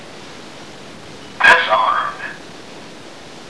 However, he also knows a couple of phrases: (click to hear Wolfgang speak)